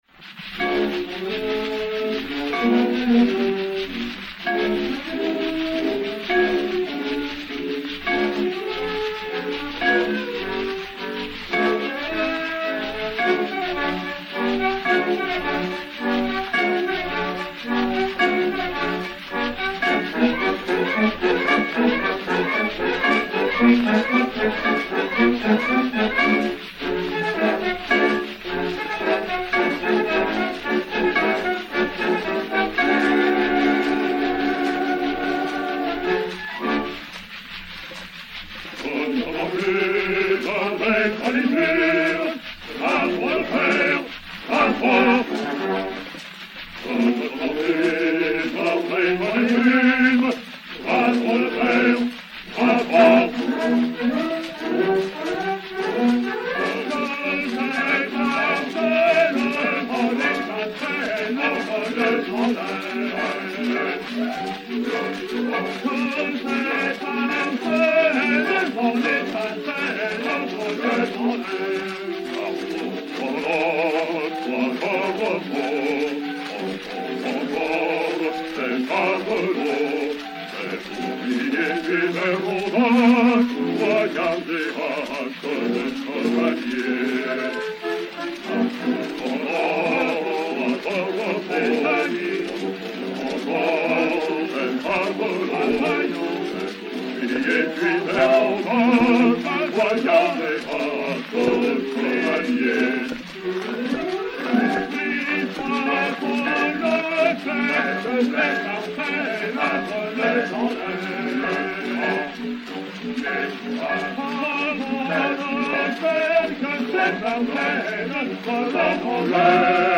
Acte I. Chœur de la Forge "Que notre enclume"
Orchestre
Pathé saphir 90 tours n° 855, réédité sur 80 tours n° 2526, enr. vers 1910